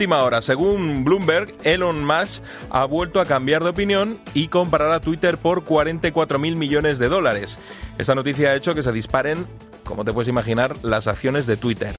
habla en 'La Linterna' sobre Elon Musk y su compra de Twitter